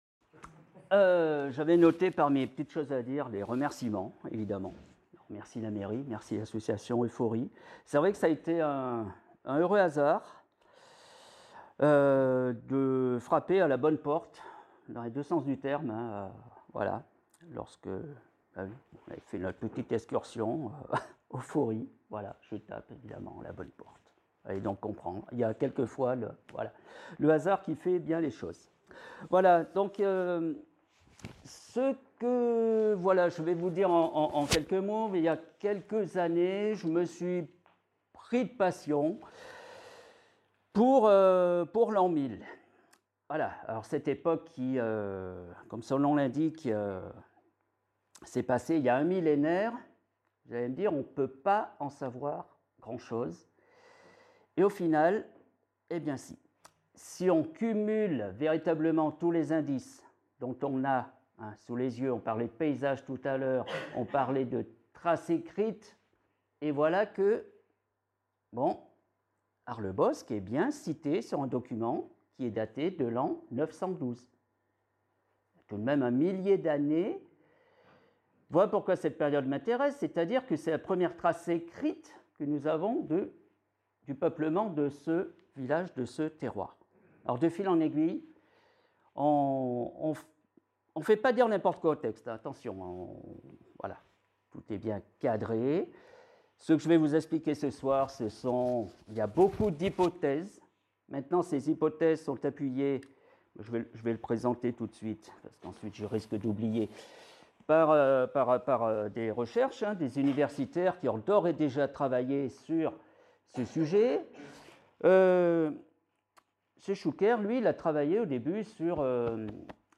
Conférence : À la découverte de « Arlabòsc » en l’an Mil Et pourquoi pas partir à la rencontre historique du village, pour découvrir l’organisation territoriale et agricole du terroir d’Arlabòsc il y a mille ans.